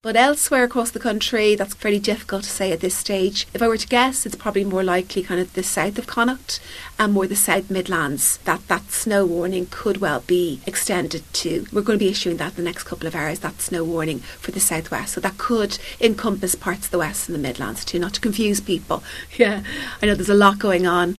Meteorologist